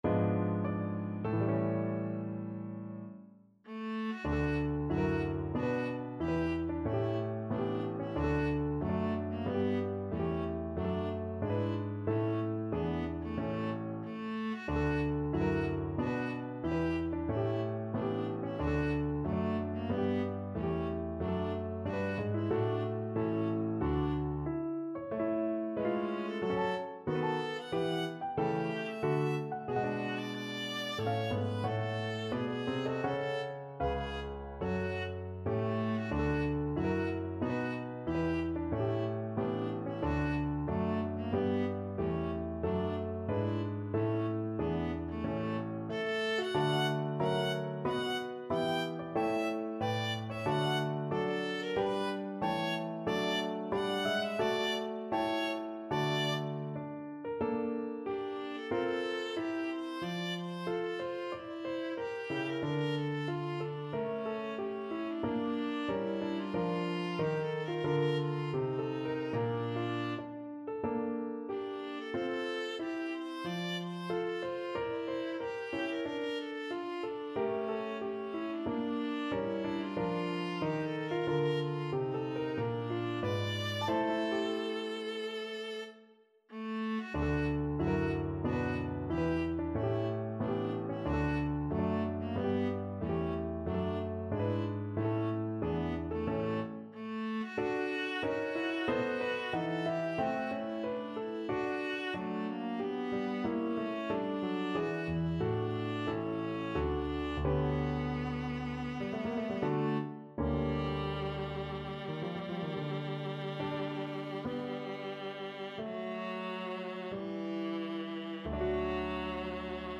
Viola
4/4 (View more 4/4 Music)
E4-G6
D major (Sounding Pitch) (View more D major Music for Viola )
Classical (View more Classical Viola Music)